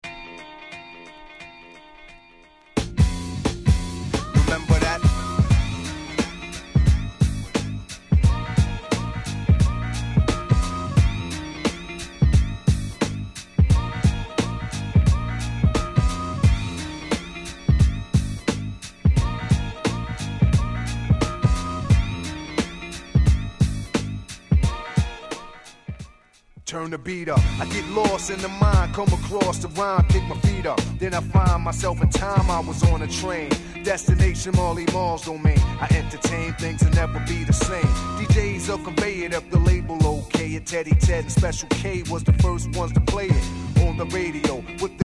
90's Boom Bap